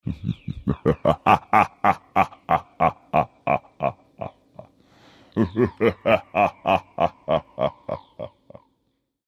Звуки пугающие
Зловещий смех
Жуткий смех
Звук зловещего хохота